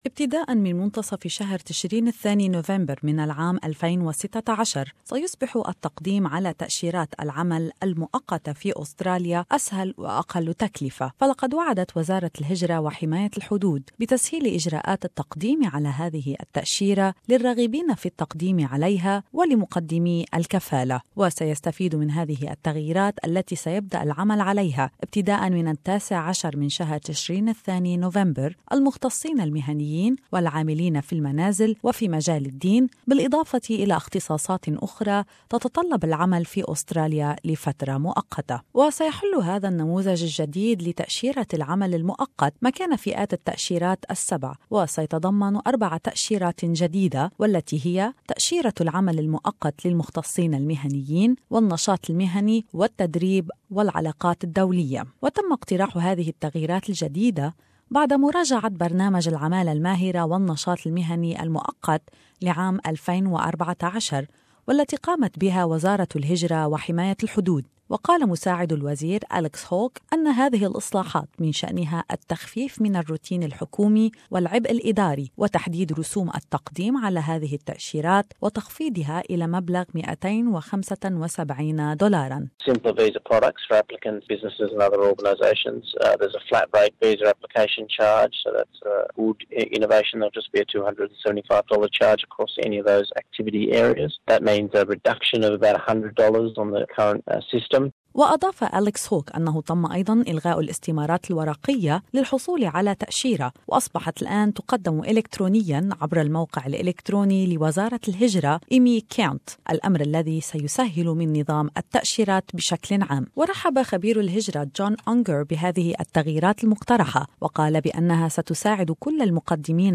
المزيد من المعلومات عن هذه التغييرات على نظام تأشيرات العمل المؤقت الجديد في هذا التقرير